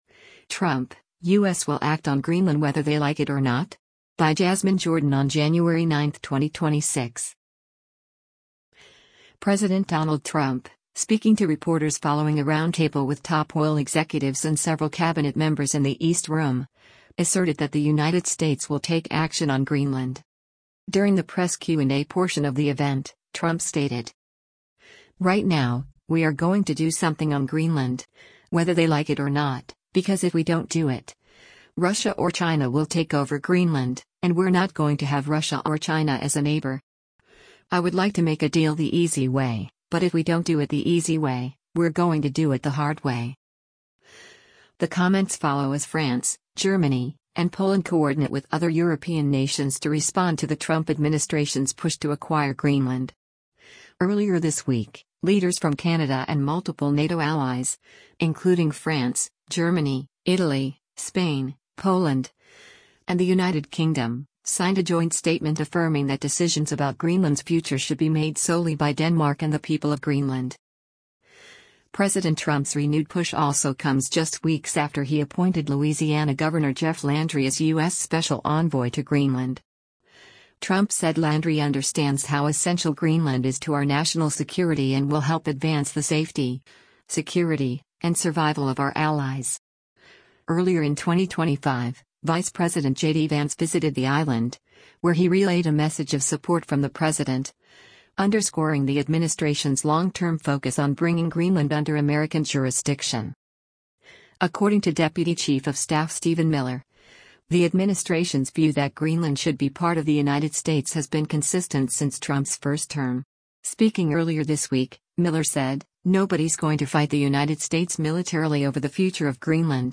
President Donald Trump, speaking to reporters following a roundtable with top oil executives and several Cabinet members in the East Room, asserted that the United States will take action on Greenland.
During the press Q&A portion of the event, Trump stated: